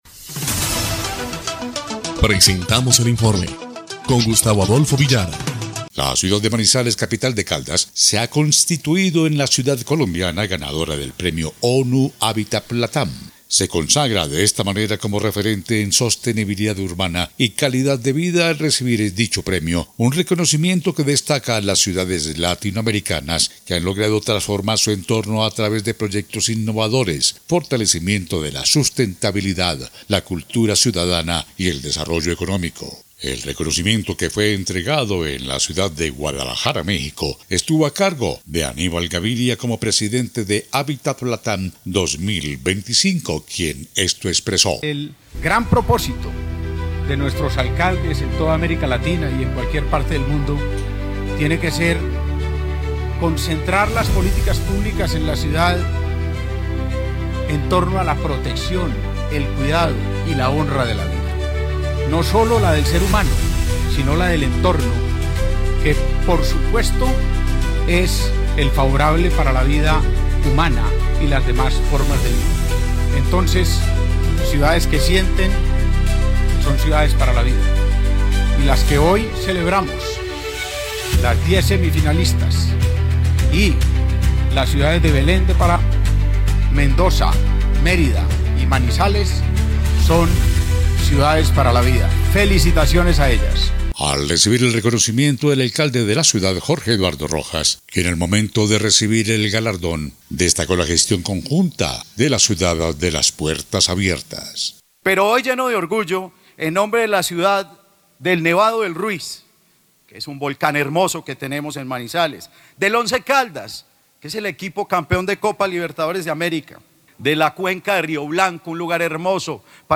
EL INFORME 3° Clip de Noticias del 26 de agosto de 2025